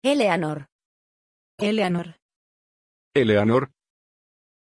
Aussprache von Éleanor
pronunciation-éleanor-es.mp3